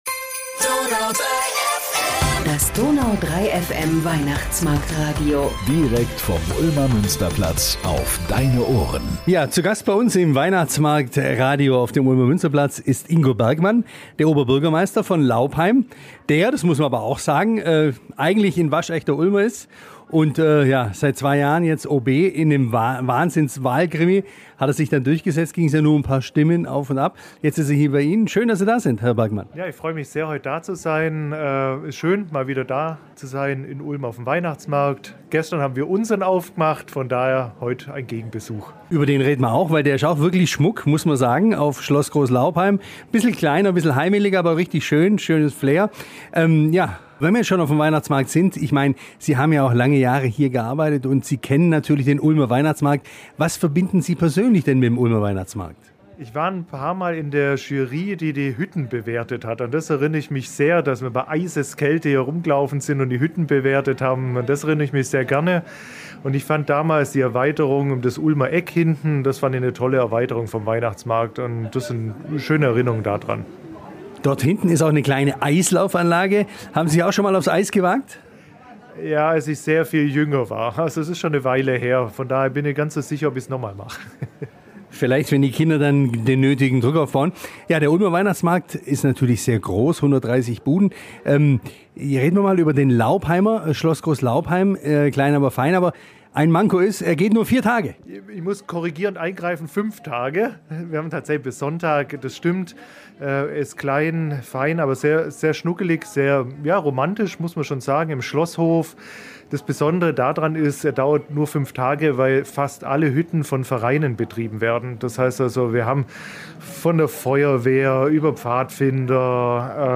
Zu Gast in unserem Weihnachtsmarktradio in Ulm war am Donnerstag der Oberbürgermeister von Laupheim Ingo Bergmann. Der gebürtige Ulmer sagt: Ulm und Laupheim verbindet viel. Und beide Städte hätten tolle Weihnachtsmärkte.